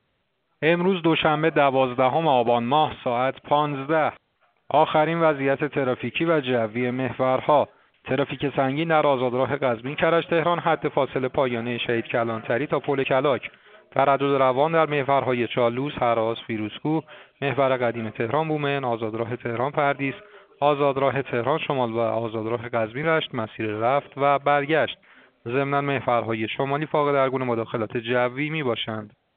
گزارش رادیو اینترنتی از آخرین وضعیت ترافیکی جاده‌ها ساعت ۱۵ دوازدهم آبان؛